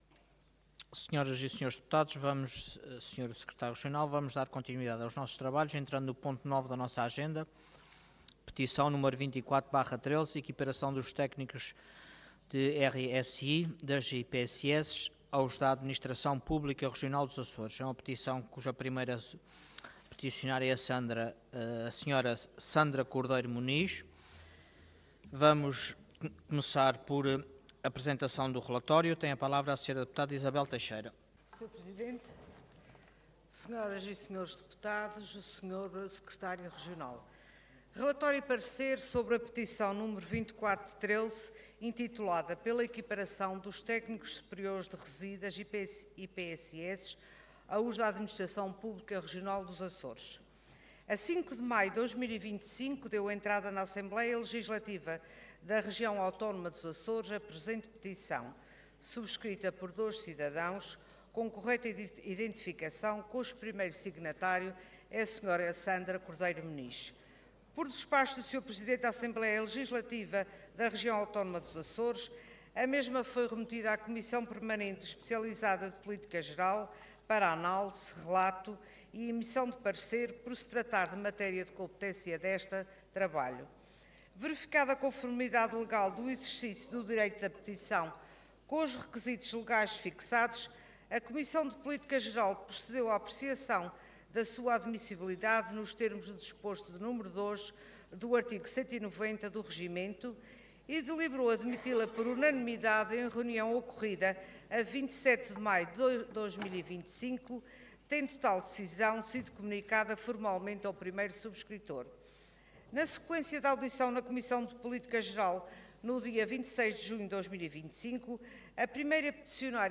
Website da Assembleia Legislativa da Região Autónoma dos Açores